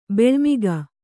♪ beḷmiga